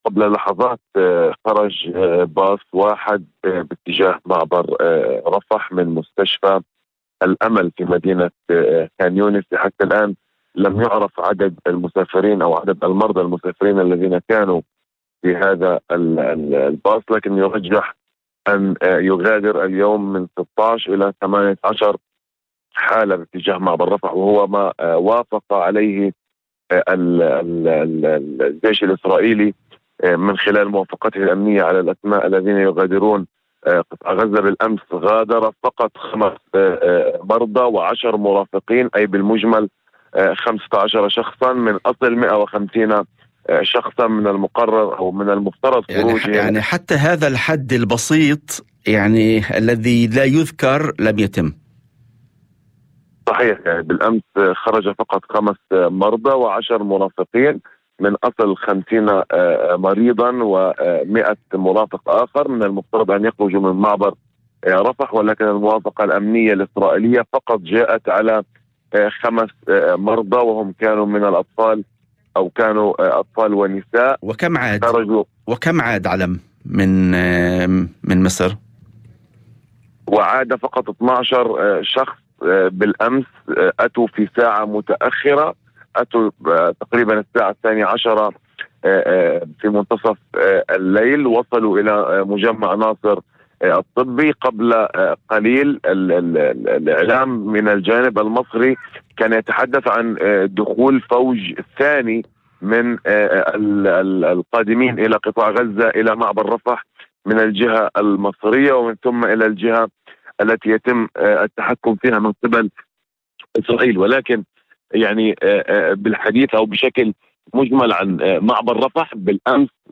وأضاف في مداخلة هاتفية ضمن برنامج "يوم جديد" على إذاعة الشمس، أن مرضى في حالات حرجة اضطروا للبقاء لساعات طويلة في ظروف غير إنسانية، رغم حصولهم على موافقات مسبقة للخروج، مشيرا إلى أن التأخير والتعقيدات الإسرائيلية تسببت بتدهور أوضاع صحية للعديد من المرضى.